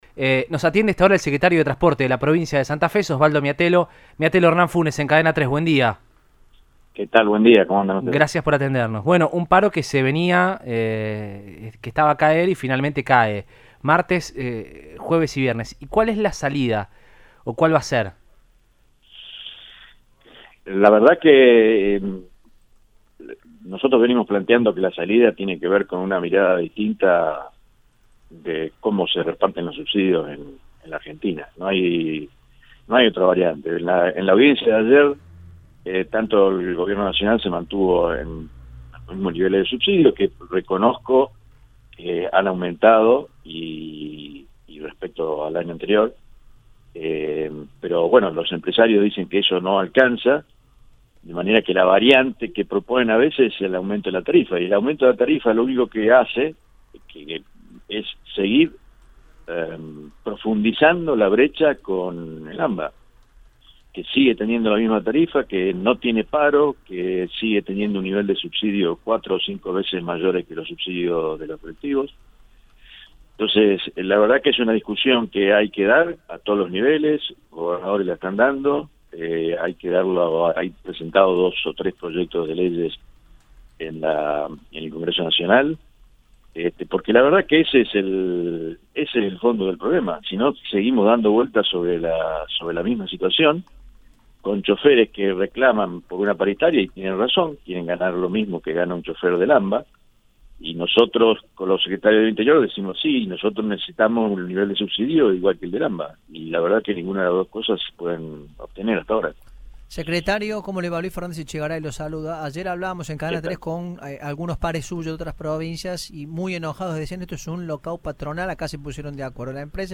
“La salida tiene que ver con una mirada distinta de como se reparten los subsidios en argentina”, indicó en Radioinforme 3, por Cadena 3 Rosario.